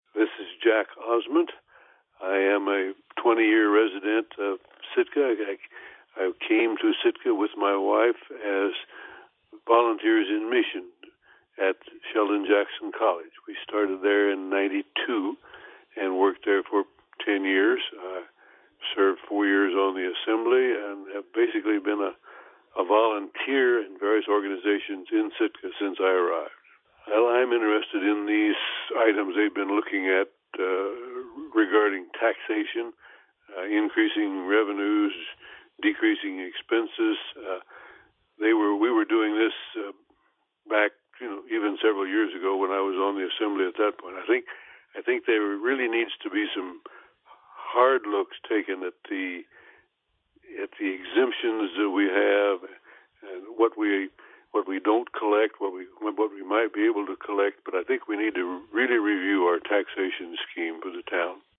We asked them all to introduce themselves to you.